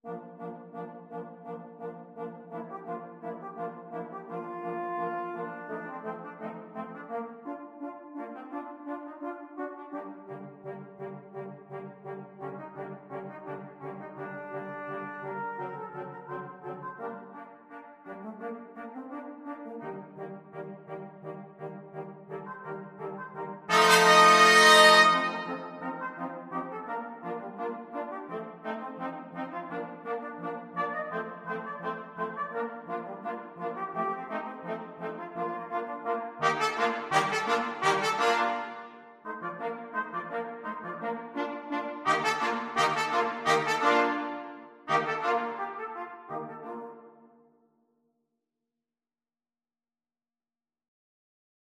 Brass Quartet version
Trumpet 1Trumpet 2French HornTrombone
= 85 Allegro scherzando (View more music marked Allegro)
4/4 (View more 4/4 Music)
Brass Quartet  (View more Easy Brass Quartet Music)
Classical (View more Classical Brass Quartet Music)